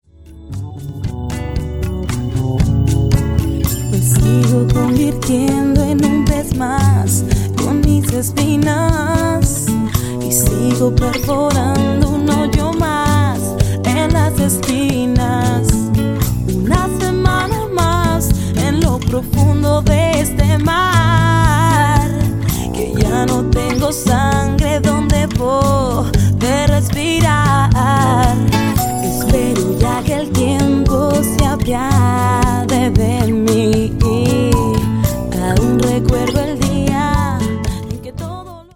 banda femenina
Música popular